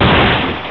Car-smash.wav